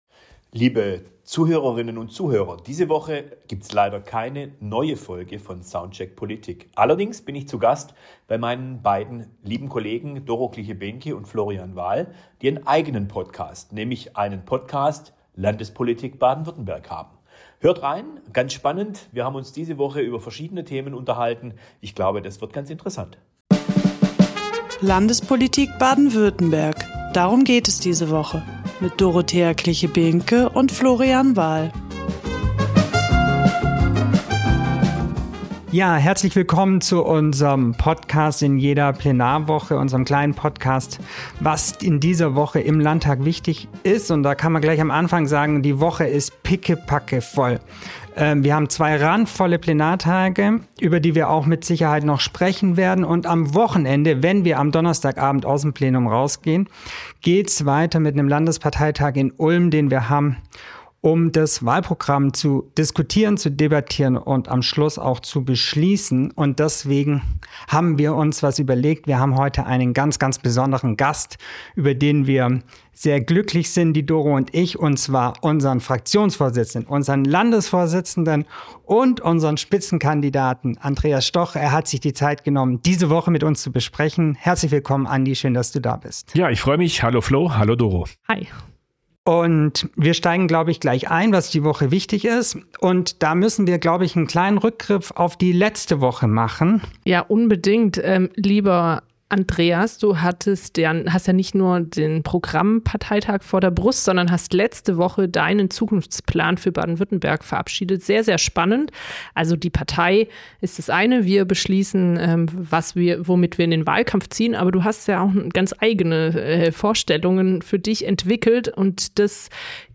Stattdessen bin ich zu Gast im Podcast meiner Landtagskollegin Dorothea Kliche-Behnke und meinem Landtagskollegen von Florian Wahl.